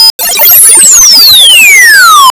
Pallas Plus Chirp Test V2
Stimulus.wav